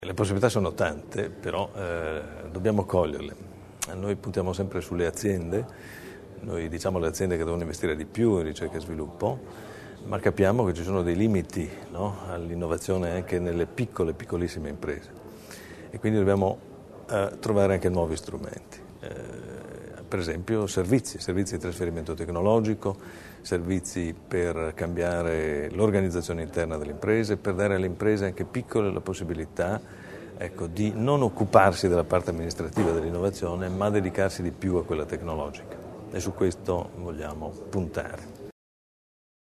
L'Assessore Bizzo sull'importanza del progetto